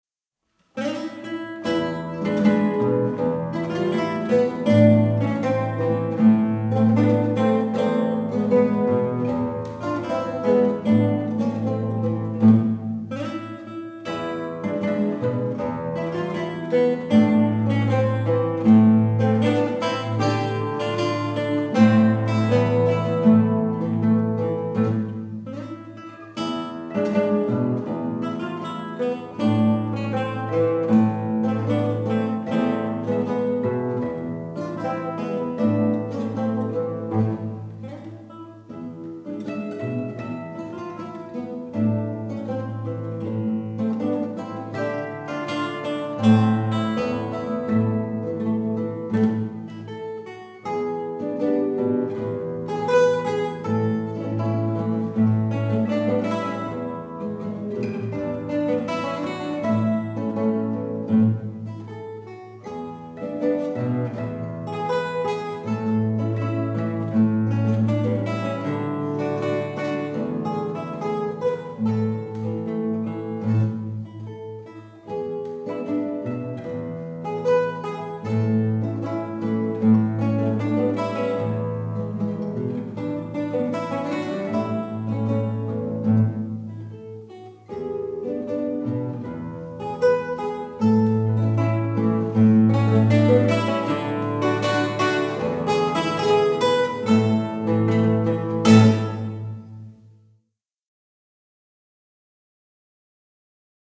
(Habanera) 1.43